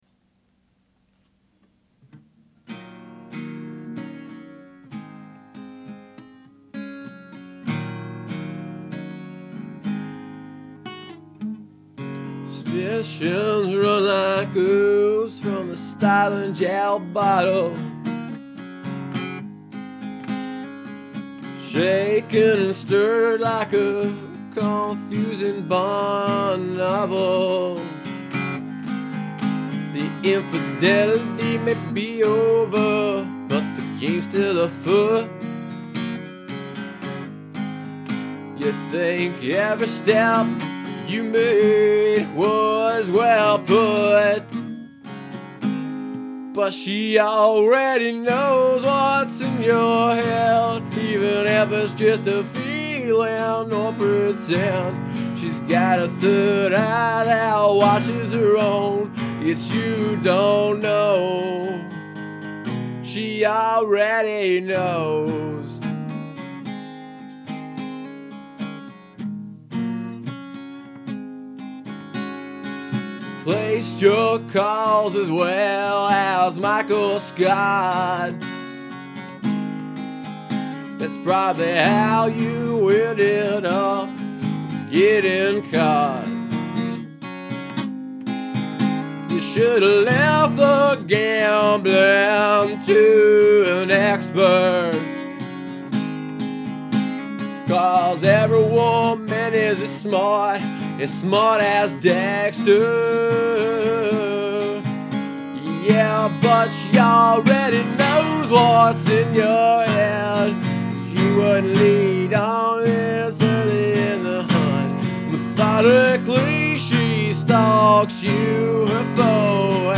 Instruments: Vocals, Rhythm Guitar
Recording Method: Zoom h4n, Audacity
Of course this demo is extremely rough, but it shows the basic structure of it.
She Already Knows (tuned down 1/2 step)